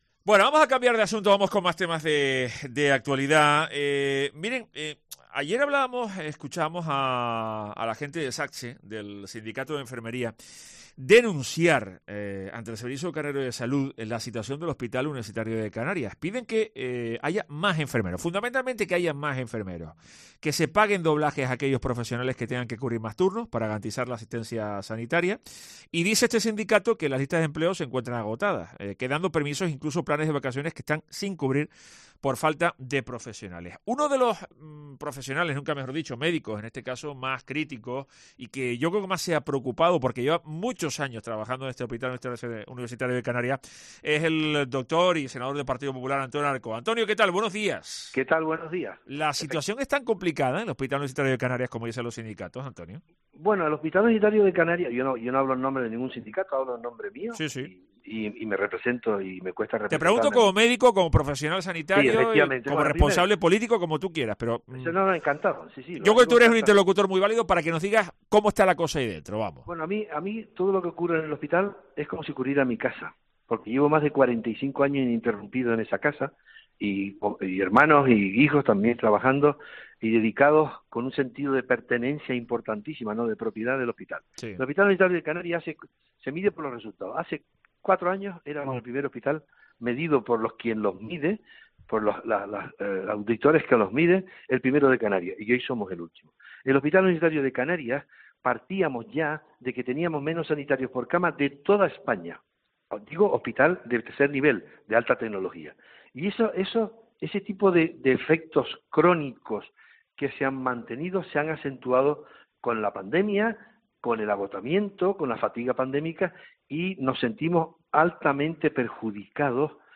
Entrevista a Antonio Alarcó